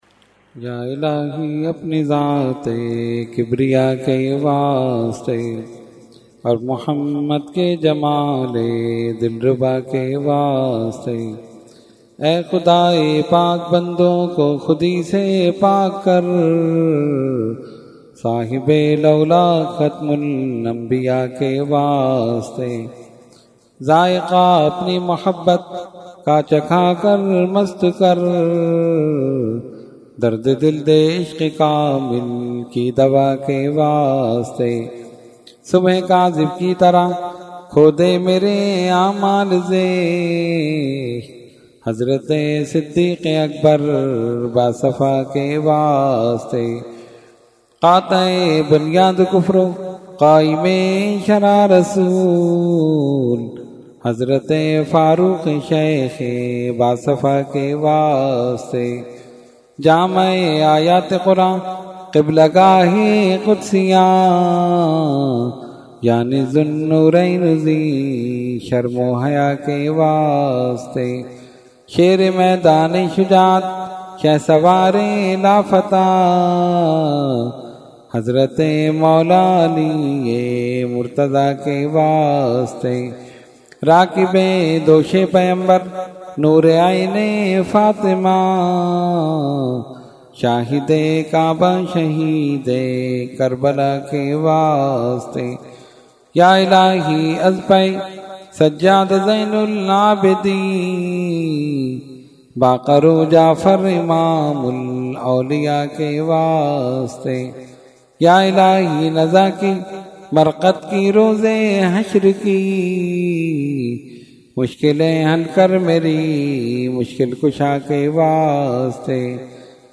Dua – Shab e Meraj 2019 – Dargah Alia Ashrafia Karachi Pakistan